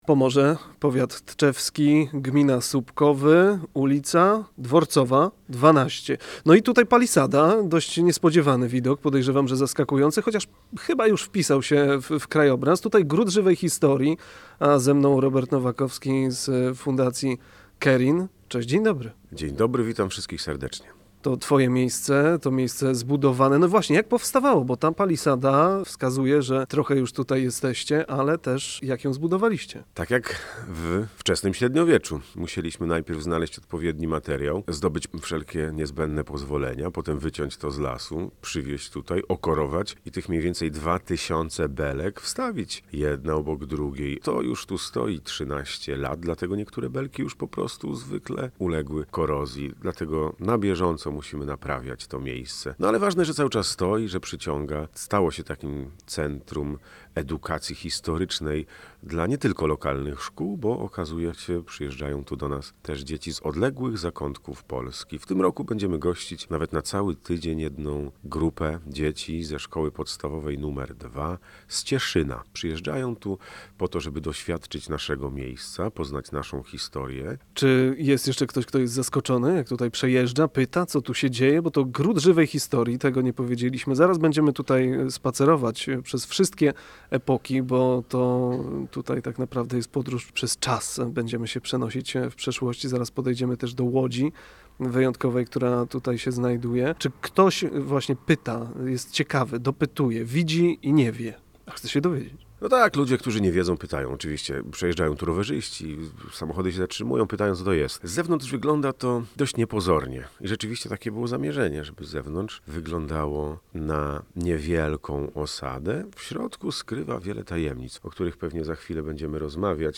W audycji „Najlepsze, bo pomorskie” odwiedziliśmy Gród Żywej Historii Fundacji Kerin w Subkowach.